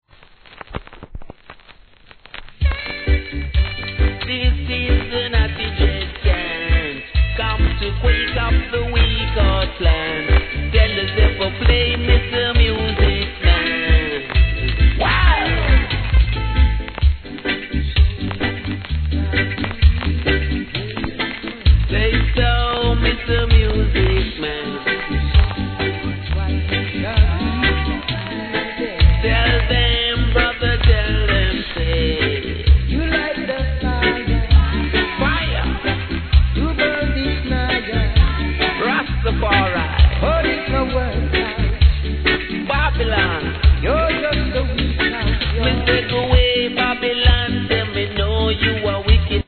序盤にややチリ入りますが徐々に落ち着きます
REGGAE